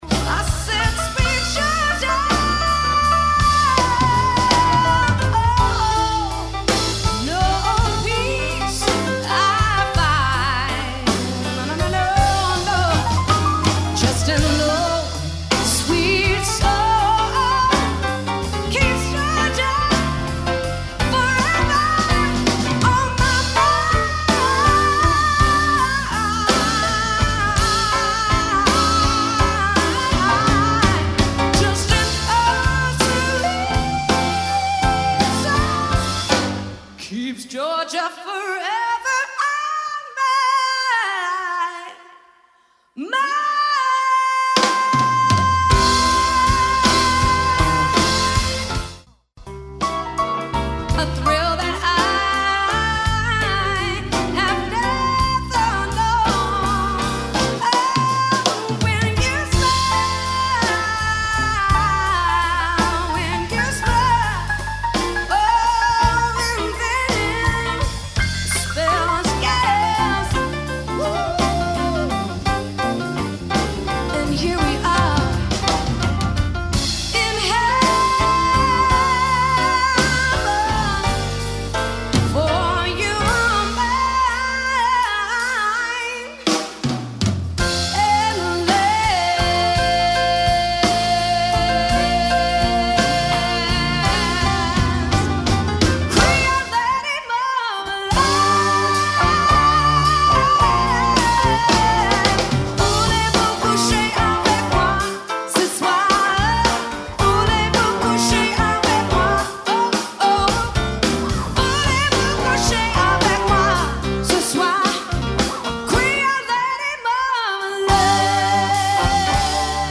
Demo Quintet